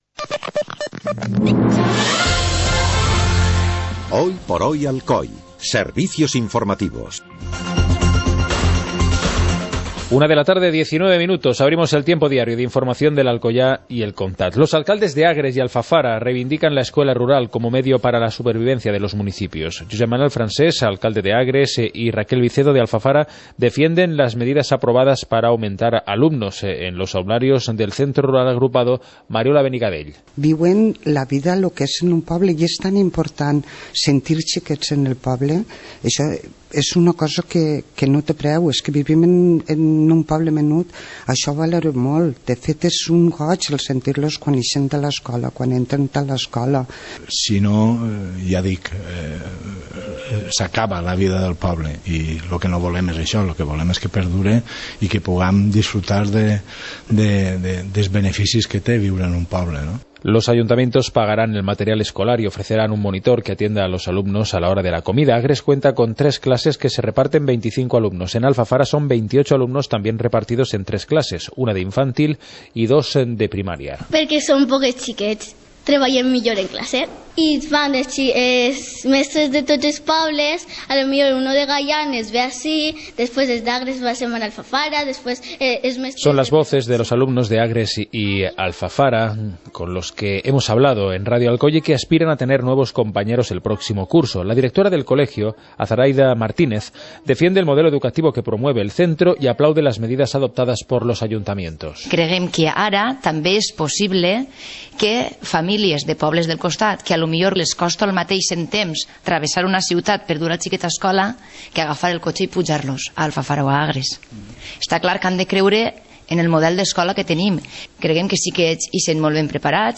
Informativo comarcal - miércoles, 04 de mayo de 2016